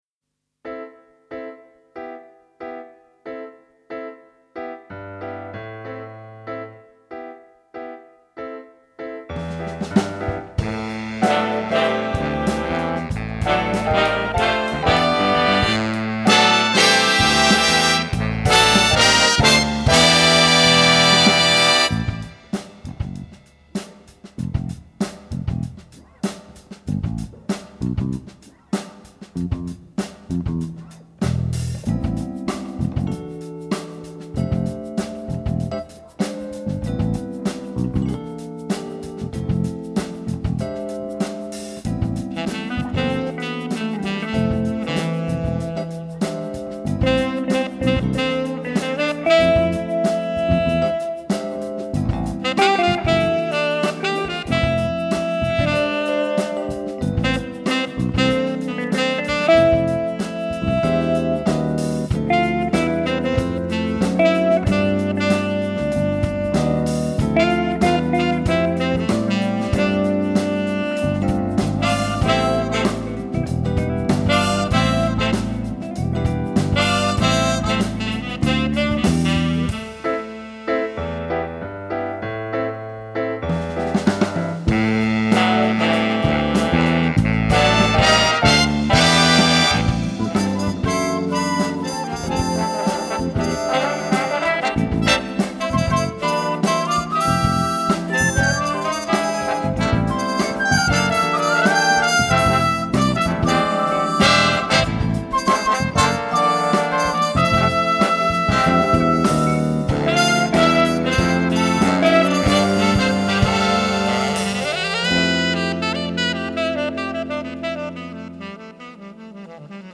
guitar
tenor sax.